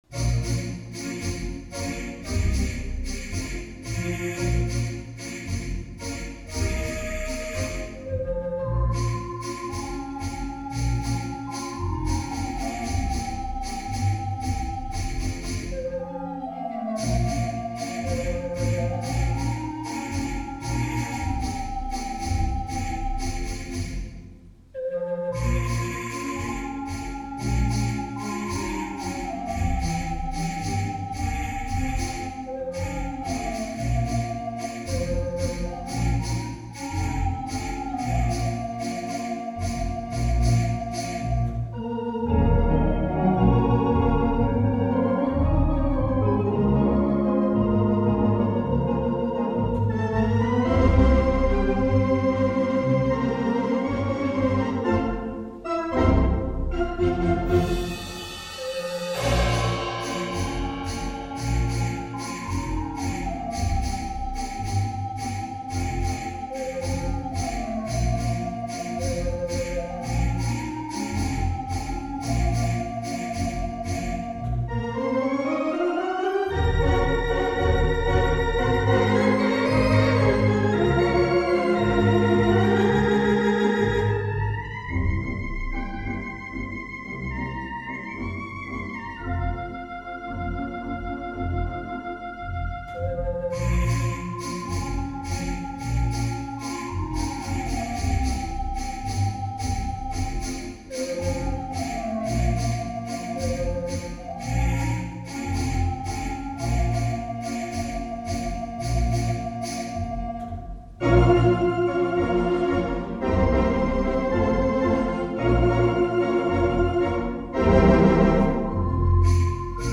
Virginia Theater Wurlitzer  /\